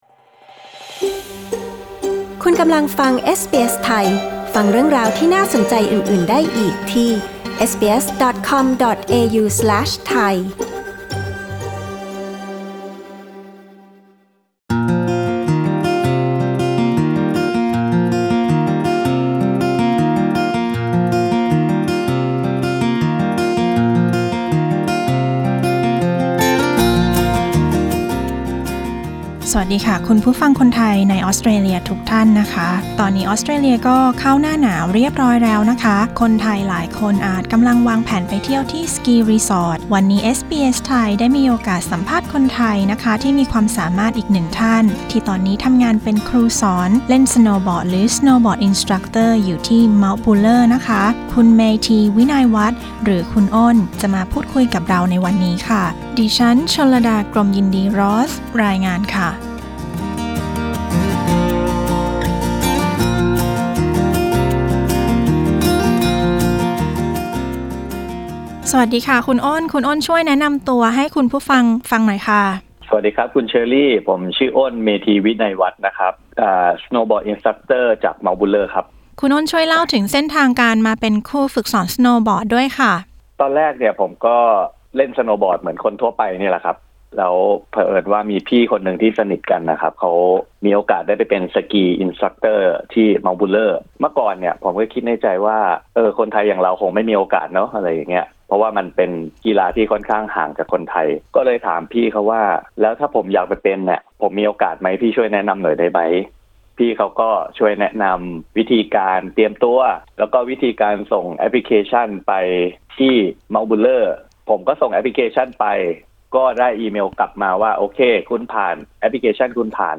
ฟังสัมภาษณ์สนุกๆ